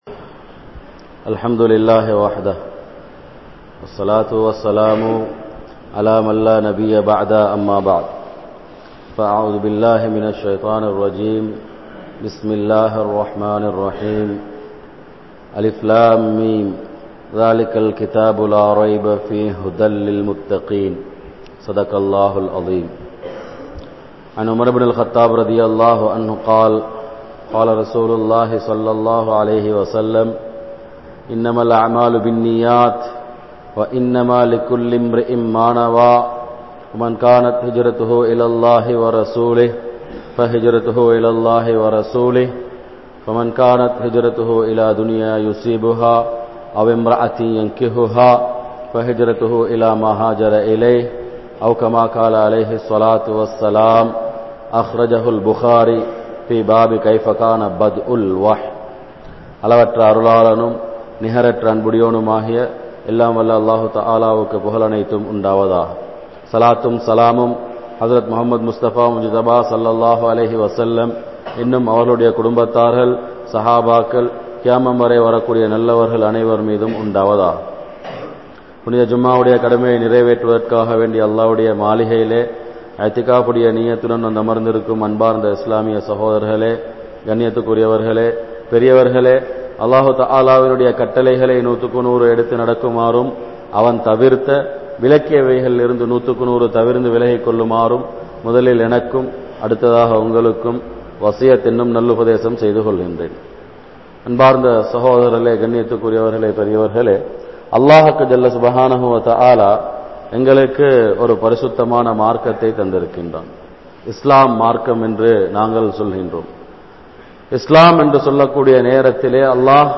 Allah`vai Marantha Muslimkal (அல்லாஹ்வை மறந்த முஸ்லிம்கள்) | Audio Bayans | All Ceylon Muslim Youth Community | Addalaichenai